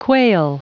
Prononciation du mot quail en anglais (fichier audio)
Prononciation du mot : quail